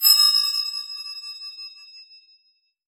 metallic_glimmer_drone_05.wav